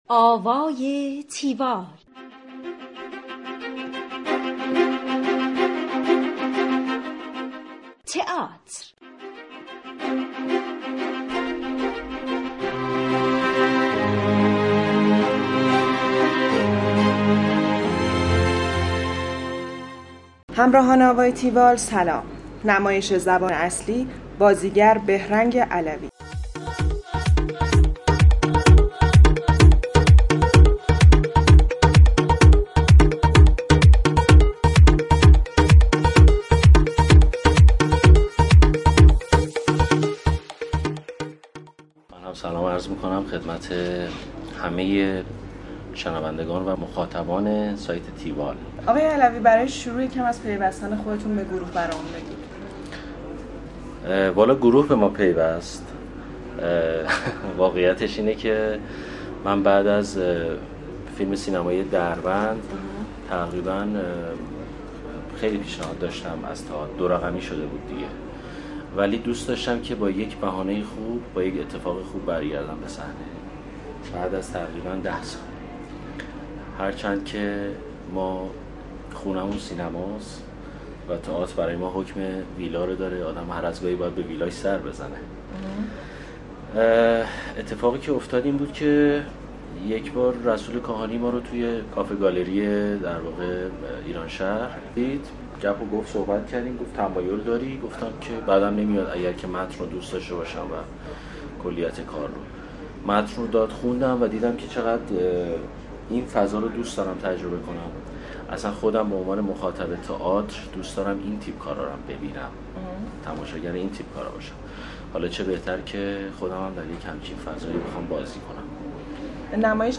گفتگوی تیوال با بهرنگ علوی
tiwall-interview-behrangalavi.mp3